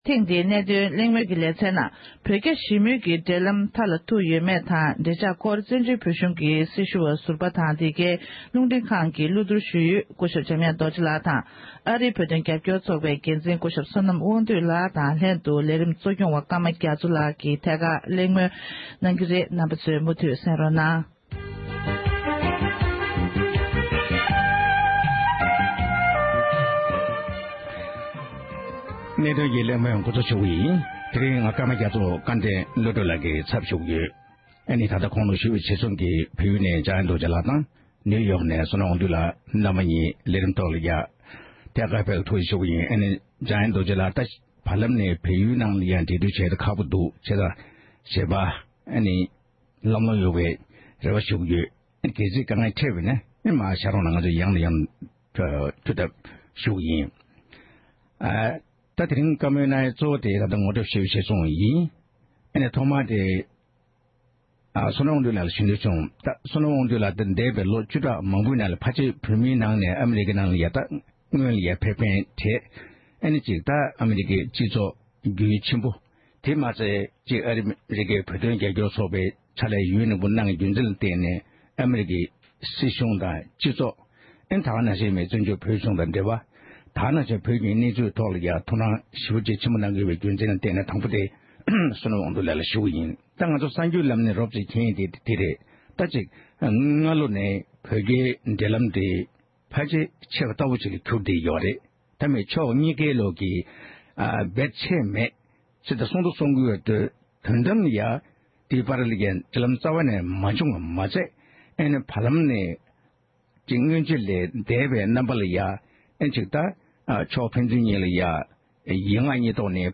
བོད་རྒྱ་འབྲེལ་མོལ་མཐའ་ལ་ཐུག་ཡོད་མེད་སྐོར་གླེང་བ།